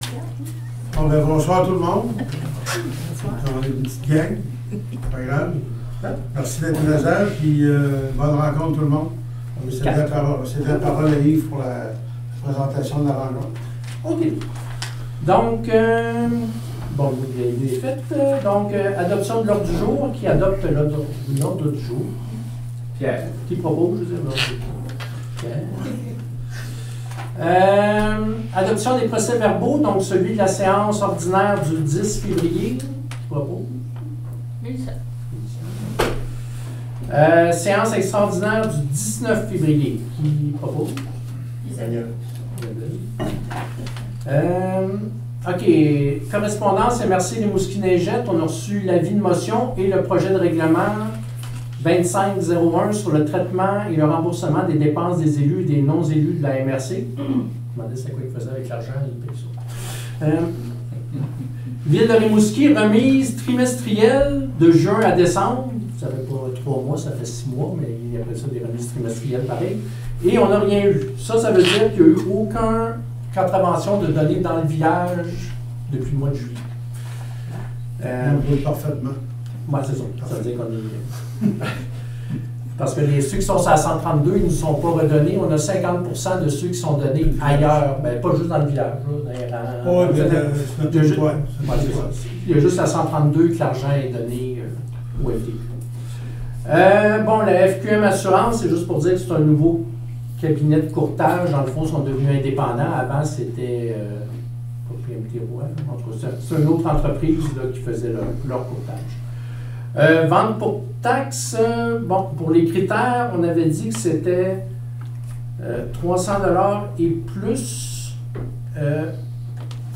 Fichier audio - Séance ordinaire du 2 juillet 2024